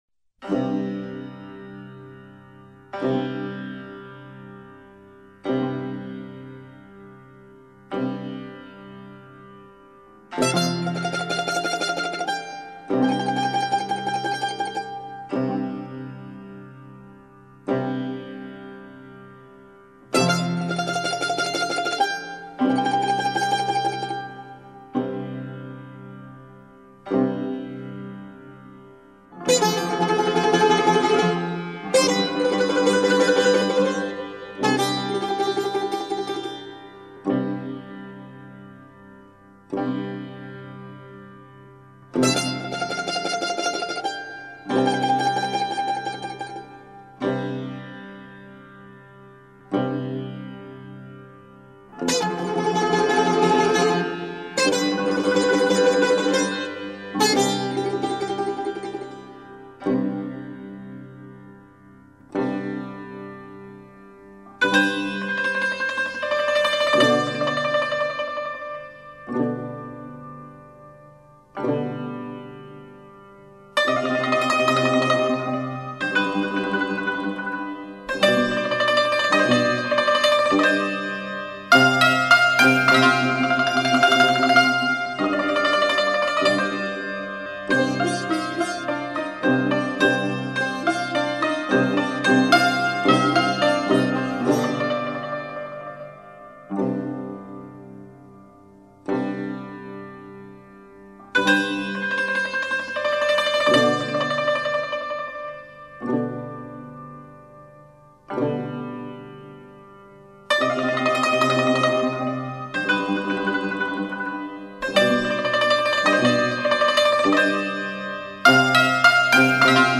خیلی اهنگ غمناکی هست.